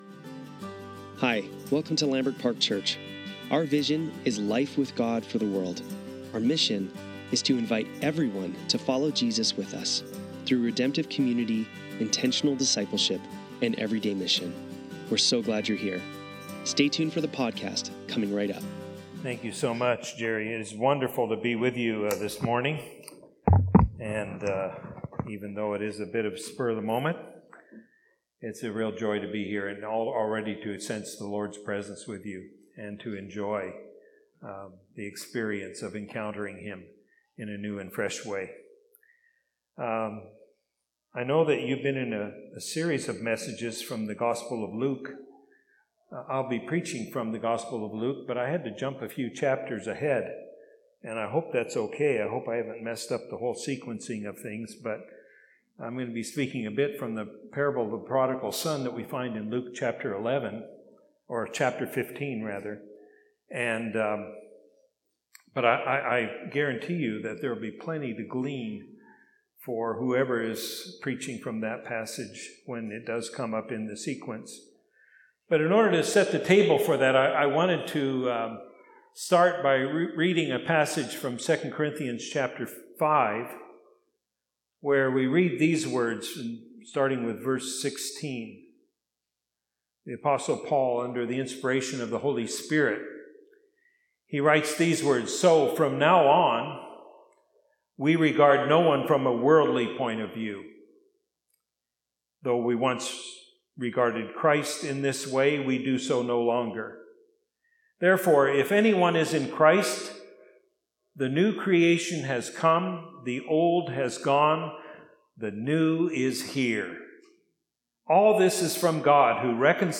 Sermons | Lambrick Park Church
Sunday Service - June 22, 2025